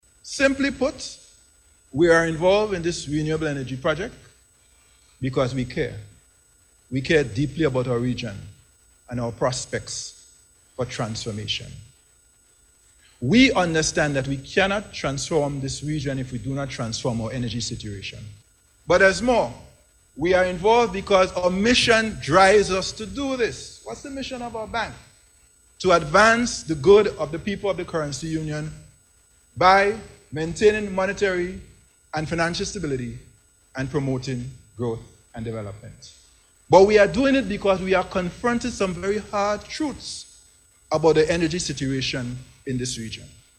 Speaking at the signing ceremony, Governor of the Eastern Caribbean Central Bank, Timothy Antoine said the Project is designed to create an enabling environment for renewable energy.